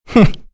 Kain-Vox-Laugh_b.wav